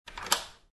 Звуки дверного замка
Щелчок замка звук